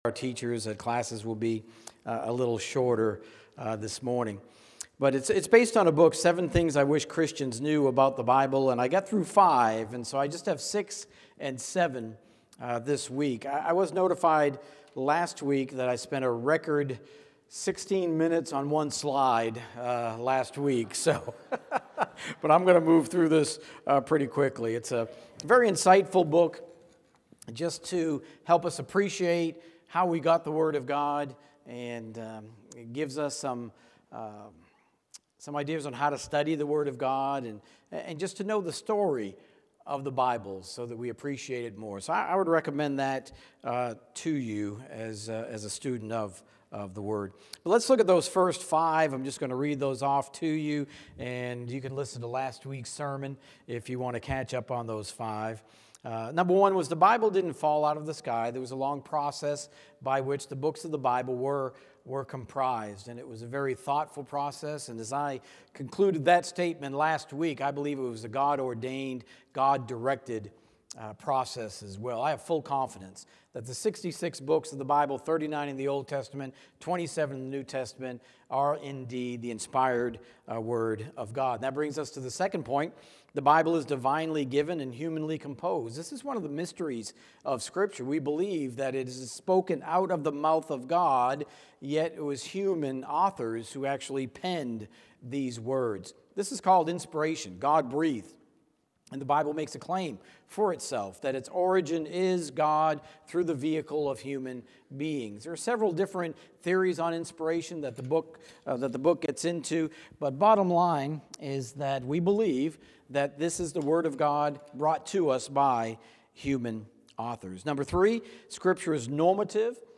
Sermons | Park Avenue Church of Christ